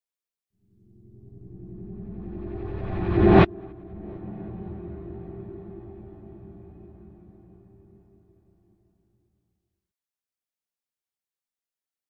Flash Back Flashback Impact with Tremolo